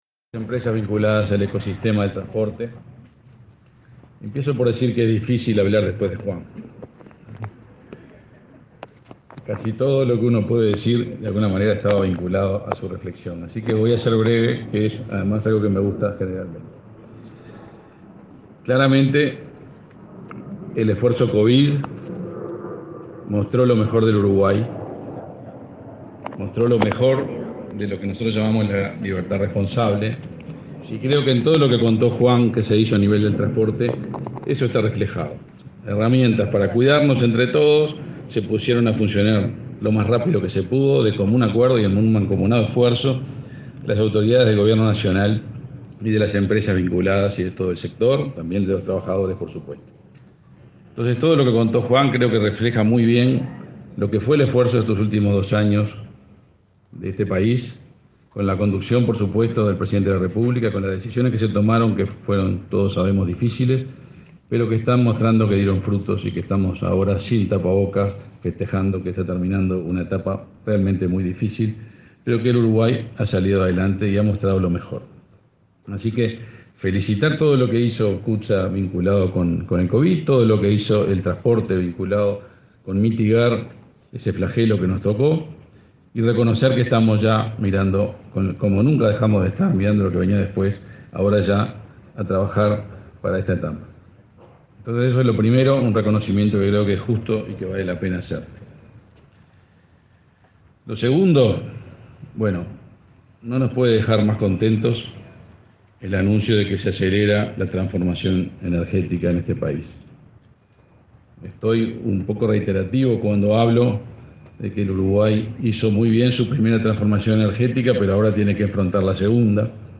Palabras del ministro de Industria, Energía y Minería, Omar Paganini
El presidente de la República, Luis Lacalle Pou, participó este 8 de marzo, junto al ministro de Industria, Energía y Minería, Omar Paganini, en el